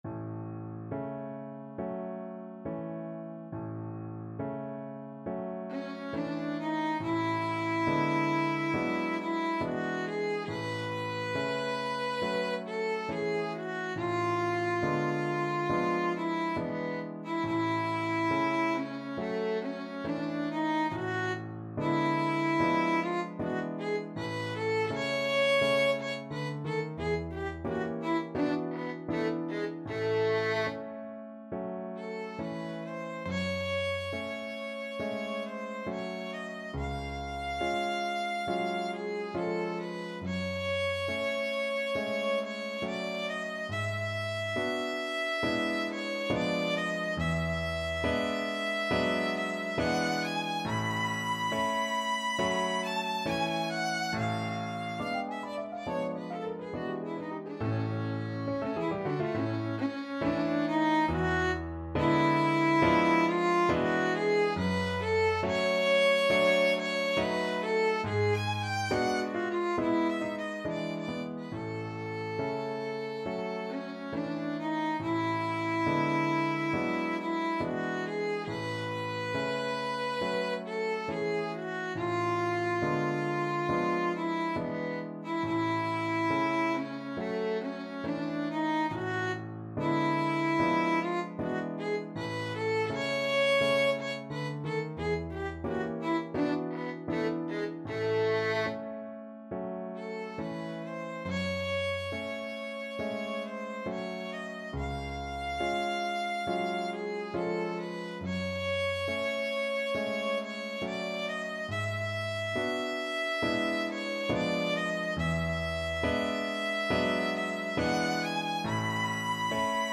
Violin
A major (Sounding Pitch) (View more A major Music for Violin )
Andante non troppo con grazia =69
4/4 (View more 4/4 Music)
Classical (View more Classical Violin Music)